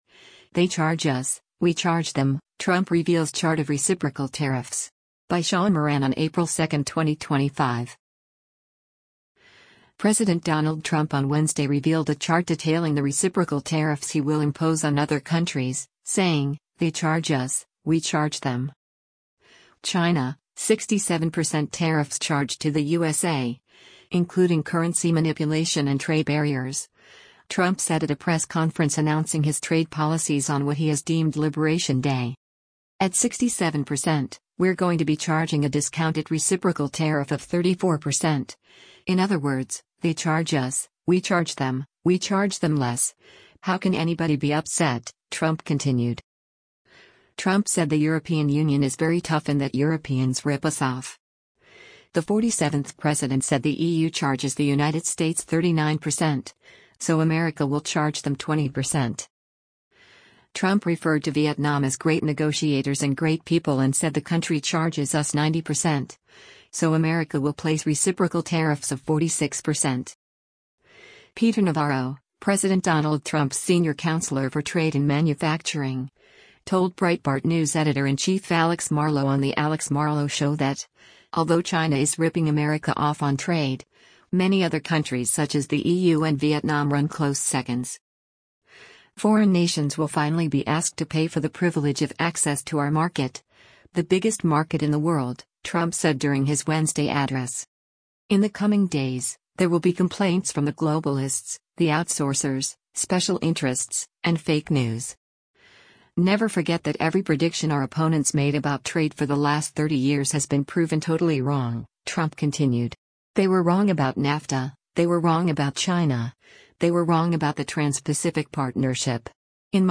“China, 67 percent tariffs charged to the USA, including currency manipulation and trade barriers,” Trump said at a press conference announcing his trade policies on what he has deemed “liberation day.”